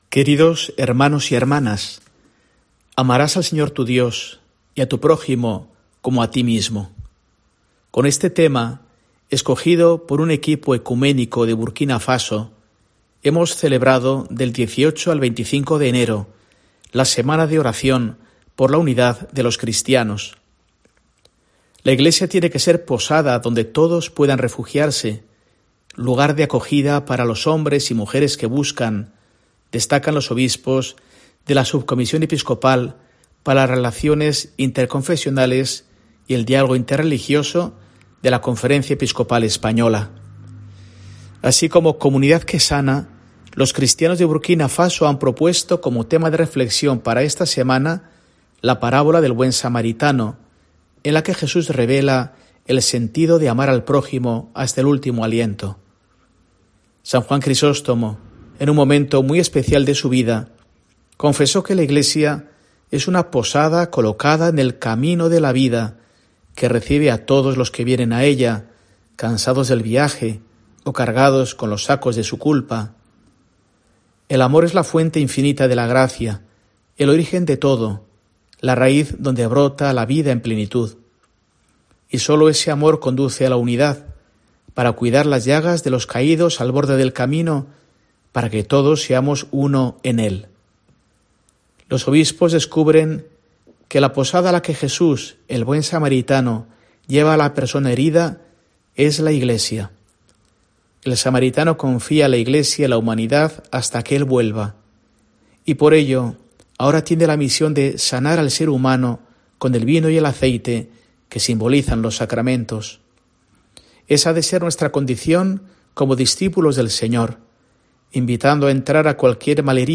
Mensaje del arzobispo de Burgos para el domingo, 27 de enero de 2024